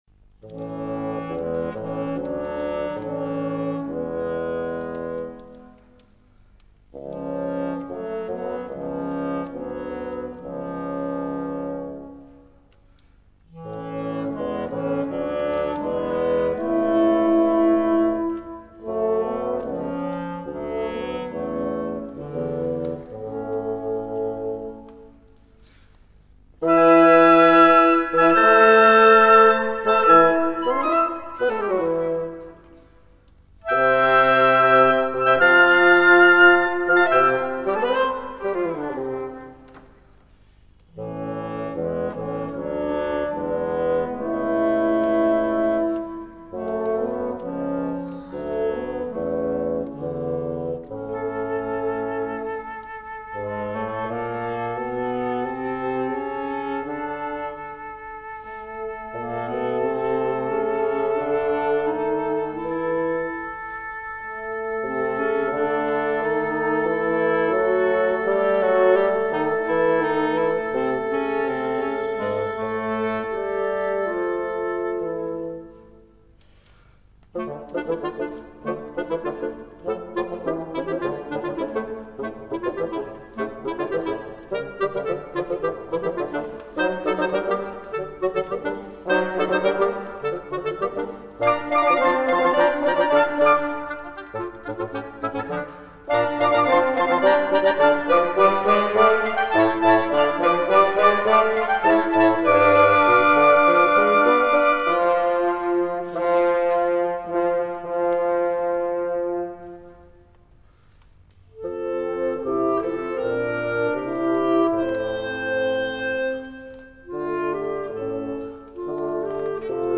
Registrazione live effettuata il 3 Gennaio 2008
nella Chiesa Regina Coeli – AIROLA (BN)